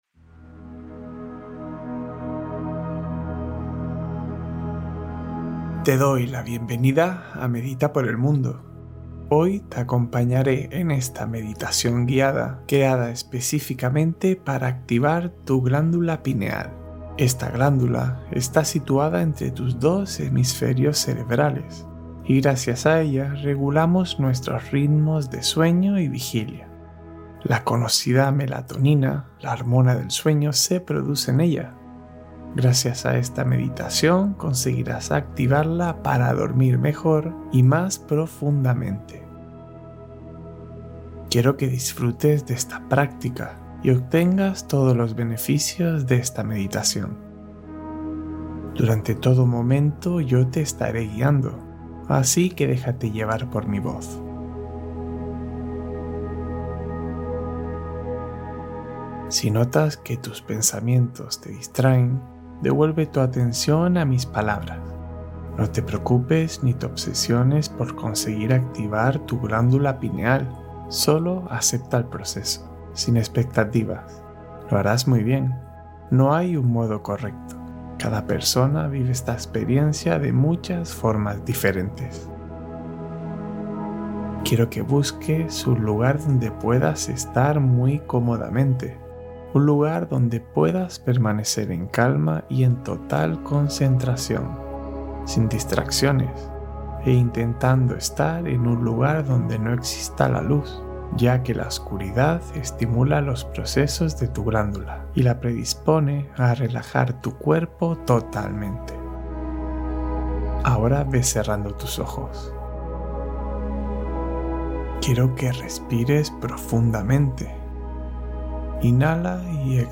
Activación Completa del Tercer Ojo: Meditación para Expansión Interior